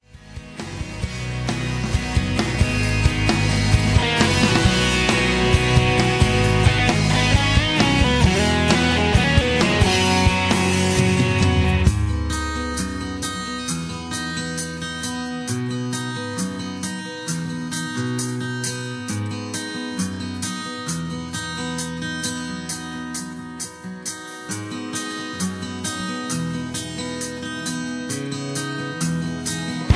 Tags: backing tracks, karaoke, sound tracks, rock